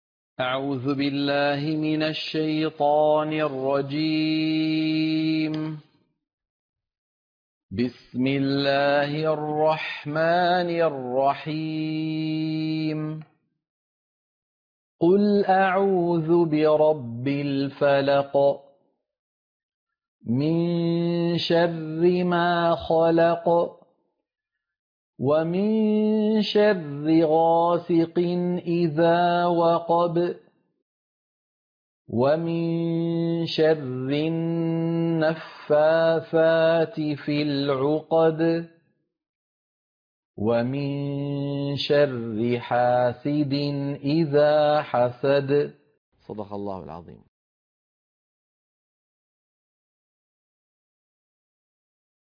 عنوان المادة سورة الفلق - القراءة المنهجية